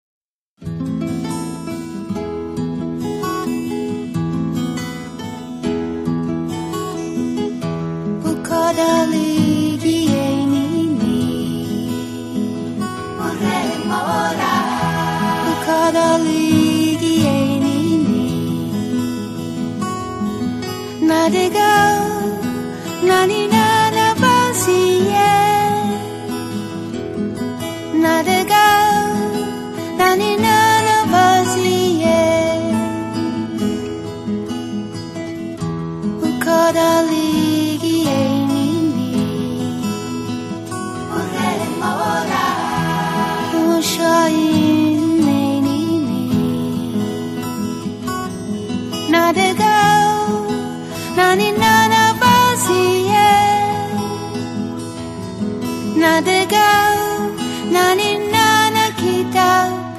chitarre
sax
voce
Un collage di melodie fresche, di lontananze evocate.